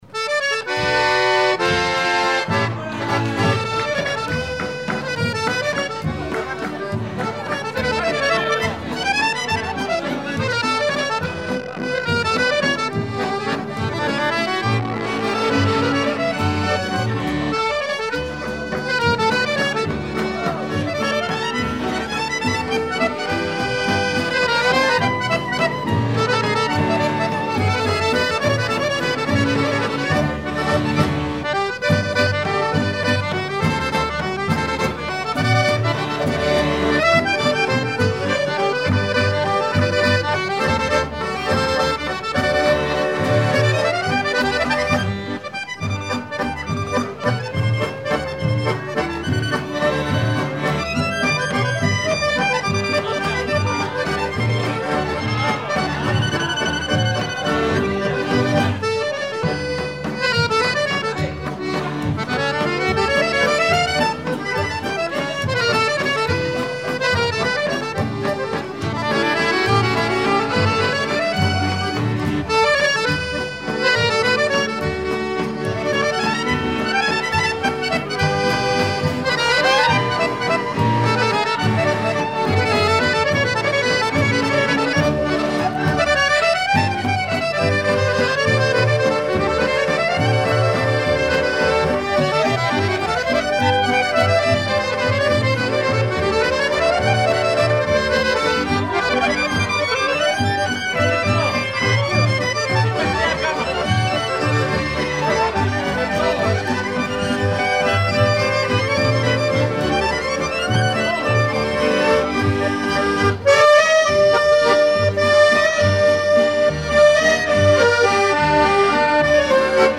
Valse musette
Informateur(s) Horo orchestre